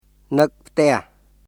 [ヌック・プテアハ　nɯk pʰtɛ̀əh]